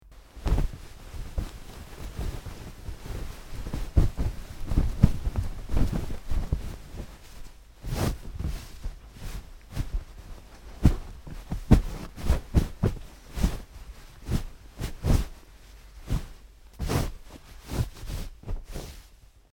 MakingUpBedSheets PE382202
Making Up The Bed With Sheets And Blanket, X2